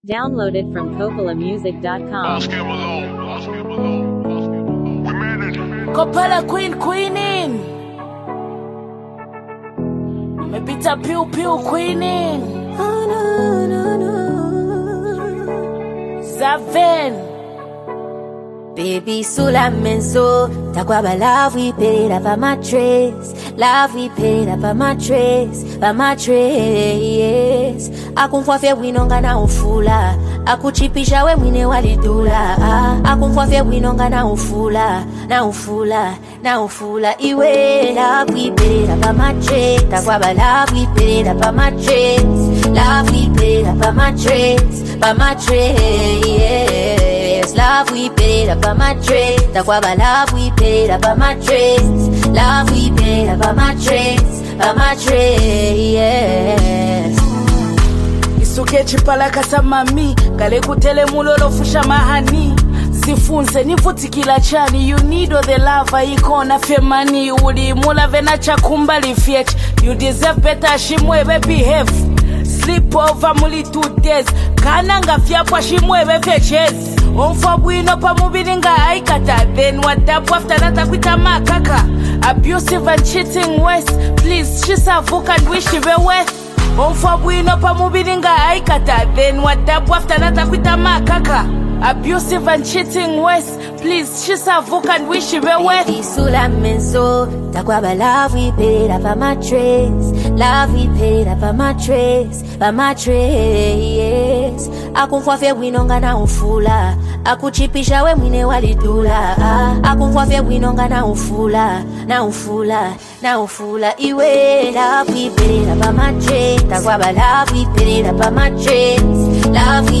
Each artist adds a unique emotional color to the song.